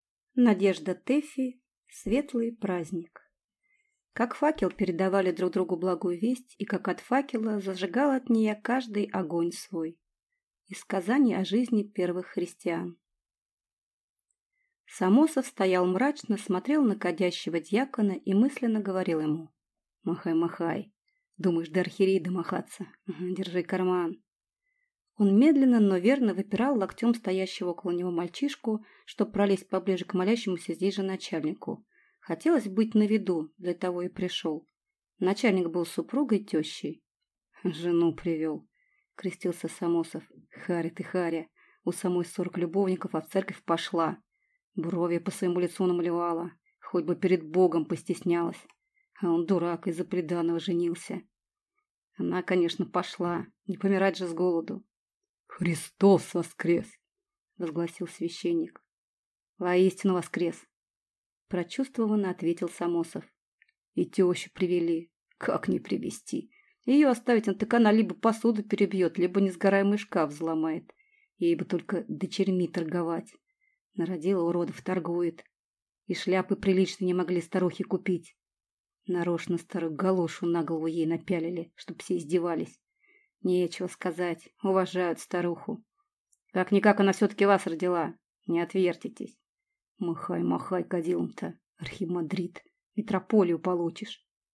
Аудиокнига Светлый праздник | Библиотека аудиокниг